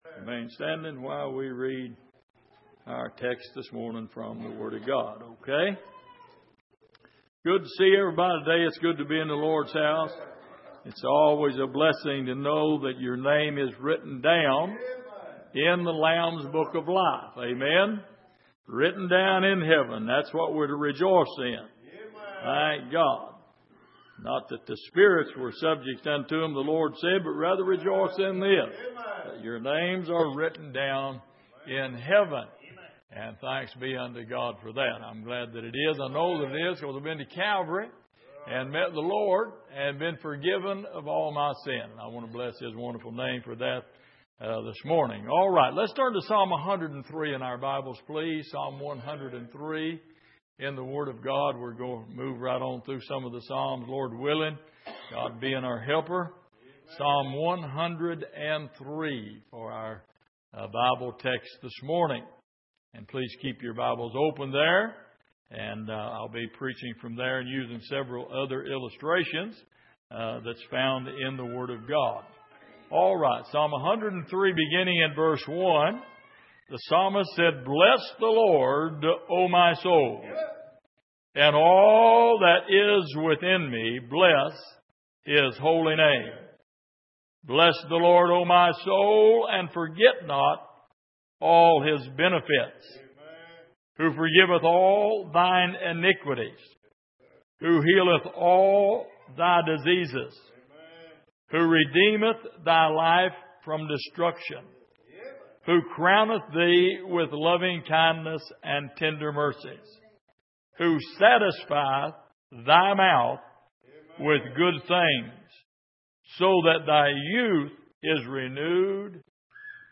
Passage: Psalm 103:1-5 Service: Sunday Morning